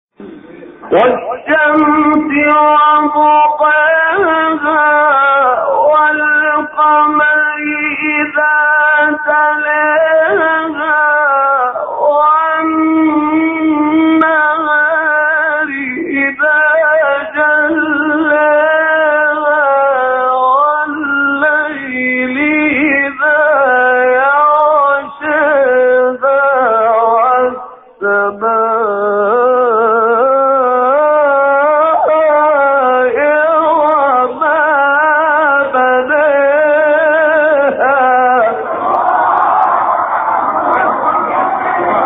گروه شبکه اجتماعی: مقاطعی صوتی از تلاوت قاریان برجسته مصری را می‌شنوید.